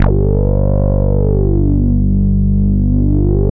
Index of /90_sSampleCDs/Trance_Explosion_Vol1/Instrument Multi-samples/Wasp Bass 2
G1_WaspBass2.wav